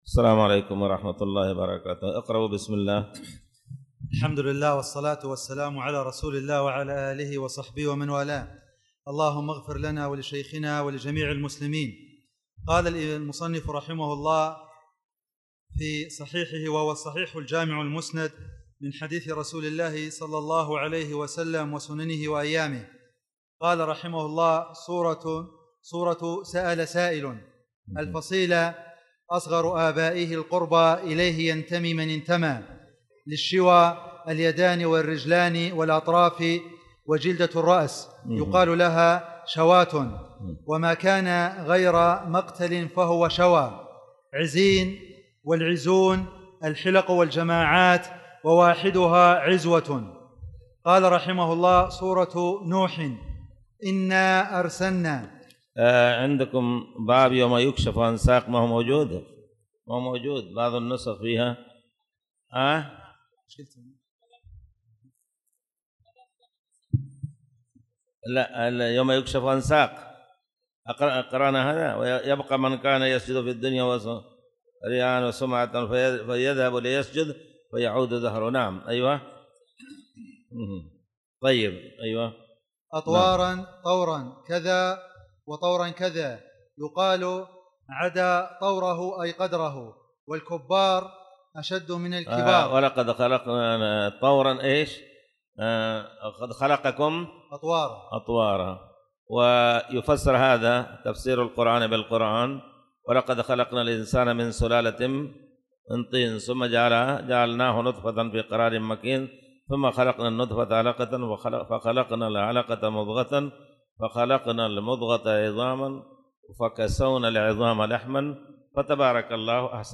تاريخ النشر ١٩ صفر ١٤٣٨ هـ المكان: المسجد الحرام الشيخ